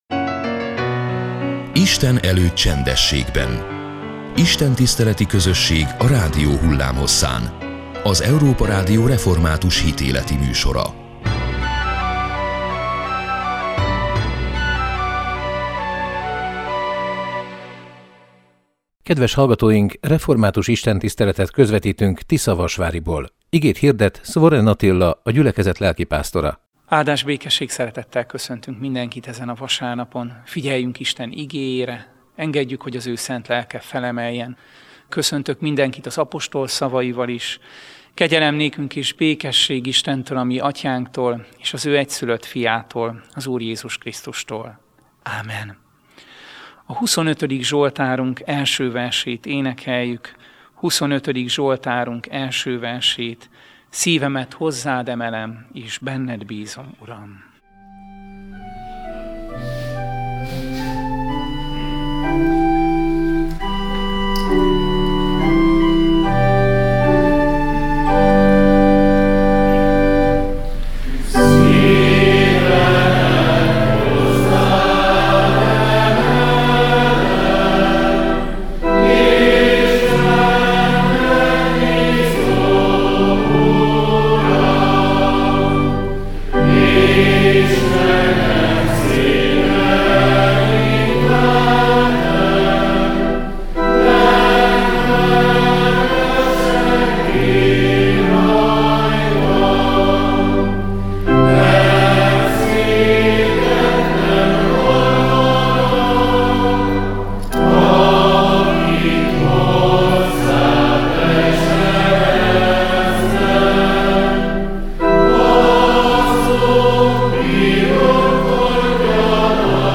Református istentiszteletet közvetítettünk Tiszavasváriból.